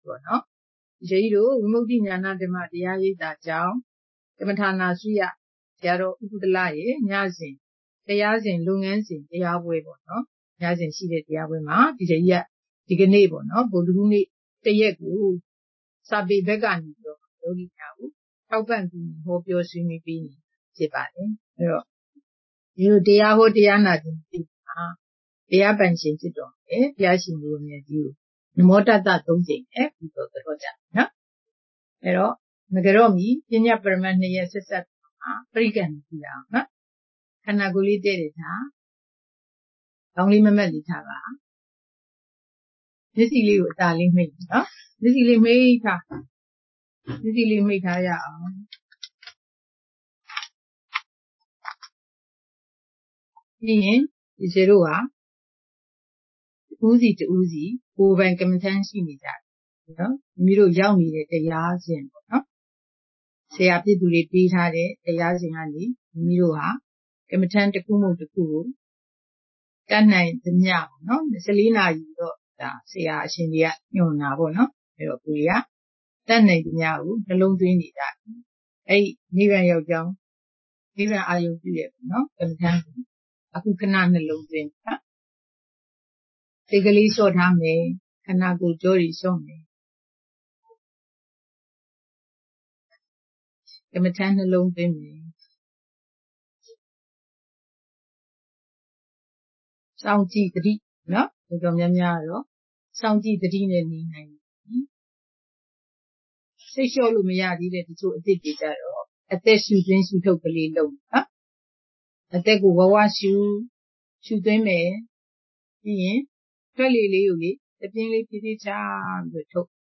Jan18 2023 ညစဉ်တရားပွဲ ဆရာမကြီး_မဇ္ဈေ (၆) ချက် အပိုင်း ၂